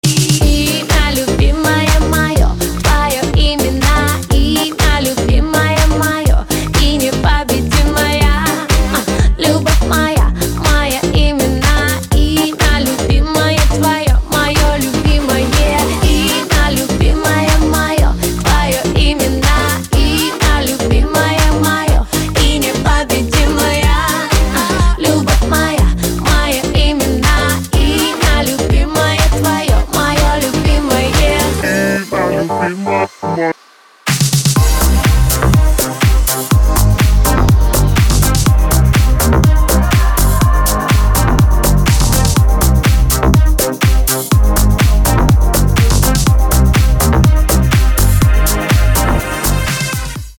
• Качество: 320, Stereo
поп
мужской голос
женский вокал
deep house
dance
Club House
электронная музыка